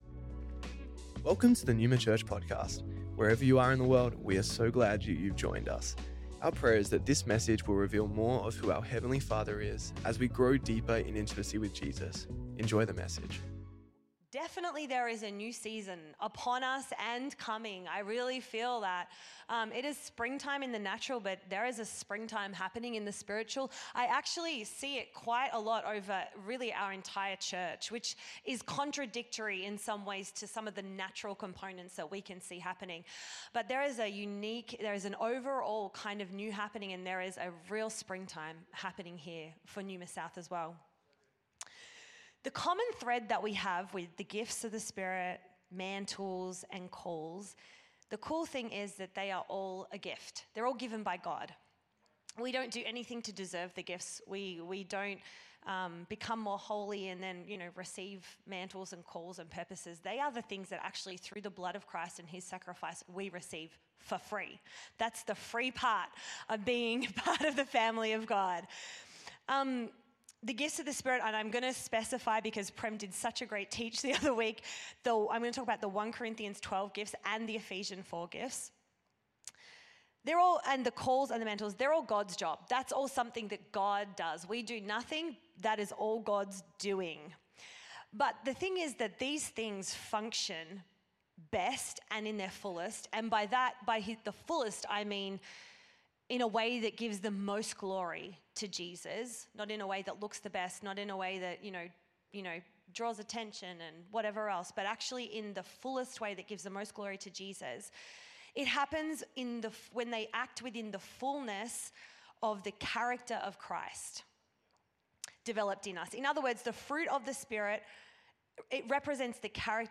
Originally Recorded at the 10AM Service on Sunday 10th November 2024